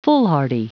Prononciation du mot foolhardy en anglais (fichier audio)
Prononciation du mot : foolhardy